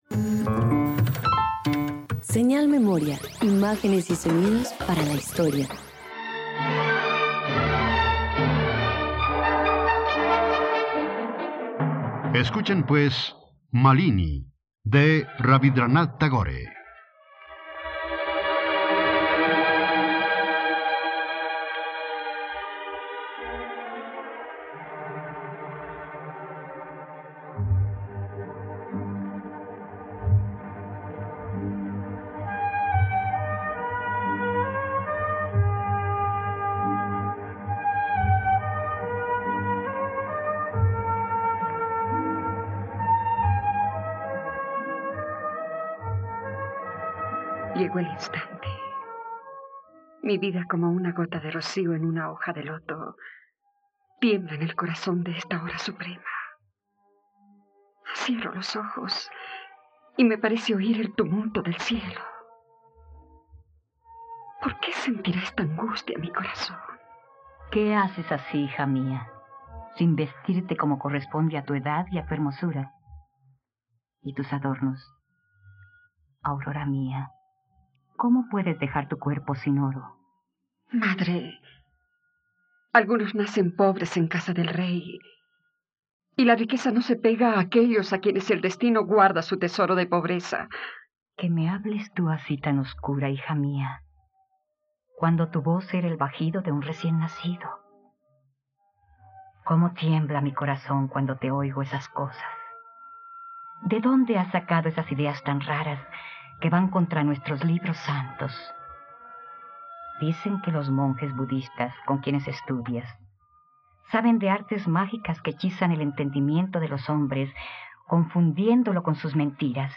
Malini - Radioteatro dominical | RTVCPlay